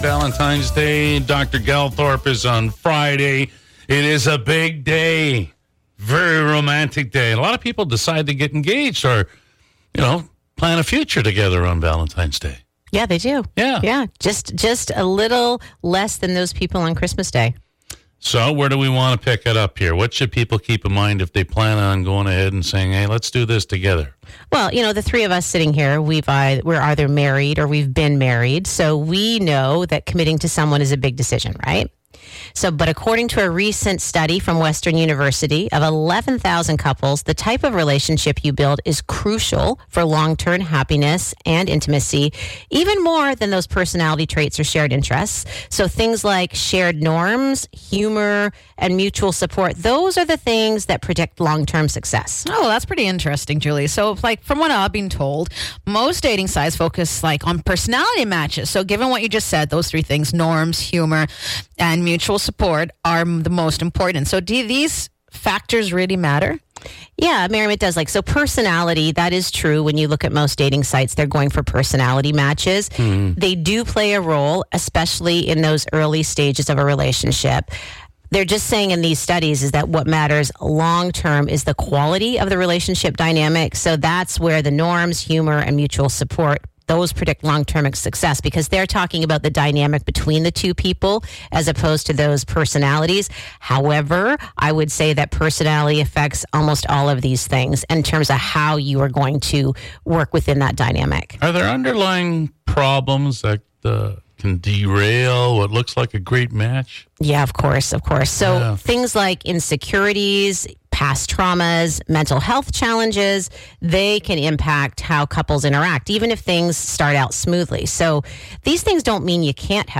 Did you know February 14th ranks second only to Christmas for the most engagements?